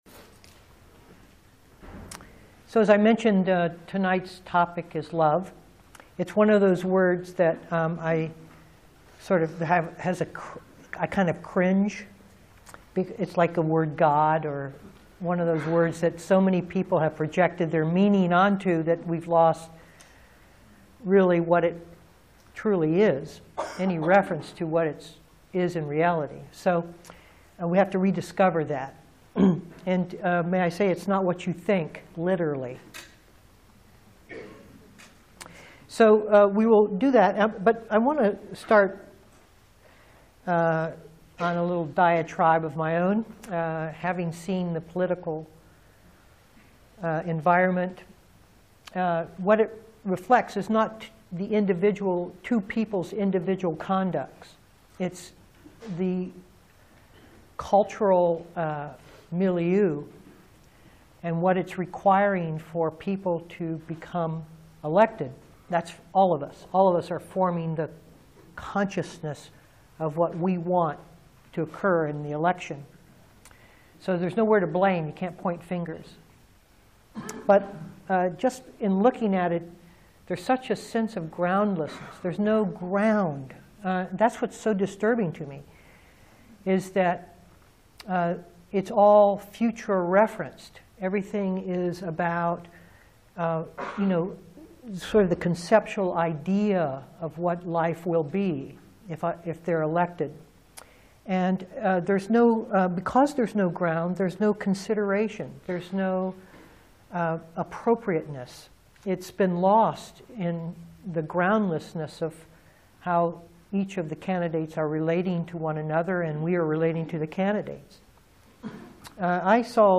2012-10-23 Venue: Seattle Insight Meditation Center Series